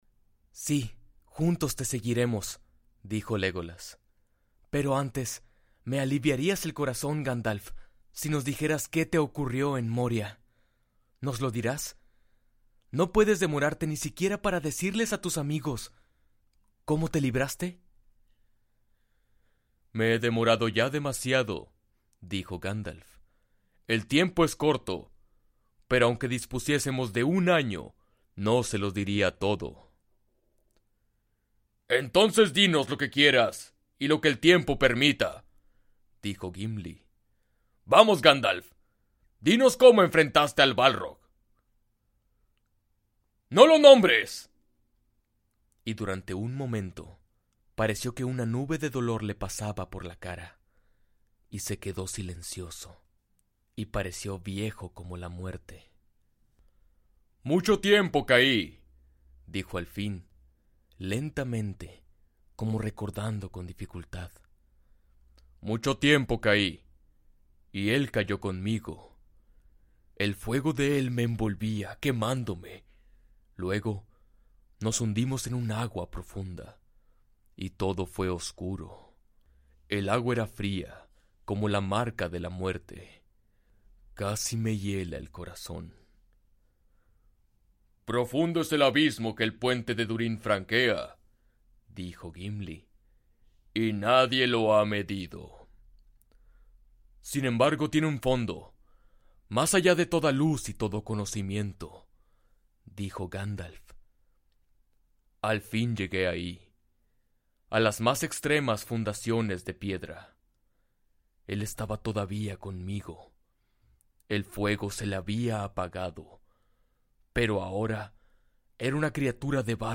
una voz versátil y barítono en español latino
Audiolibros
Prioritizo la narración clara, ritmo constante e interpretación vocal para mantener al oyente interesado.
Mic: AT-2035